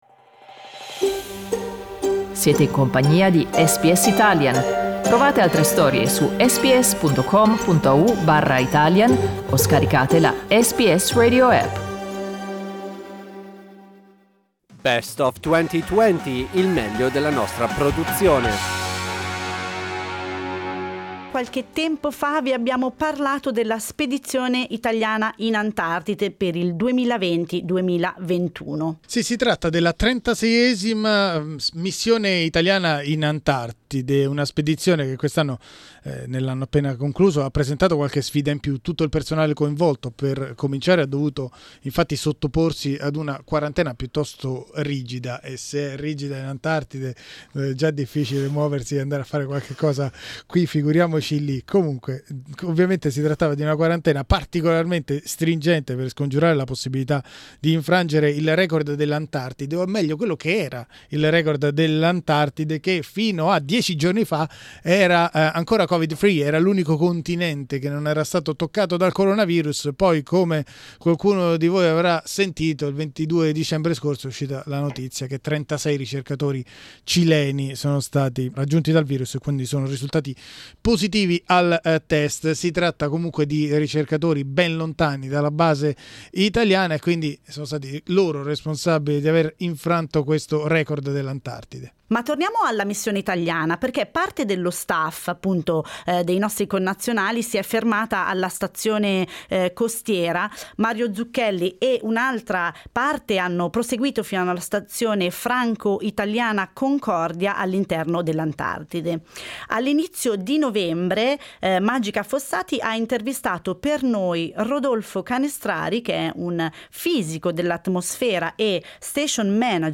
Vi riproponiamo un'intervista